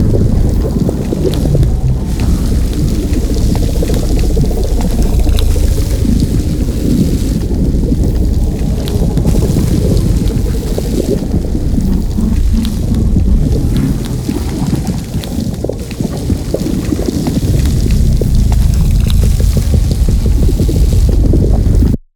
springboard_idle.ogg.bak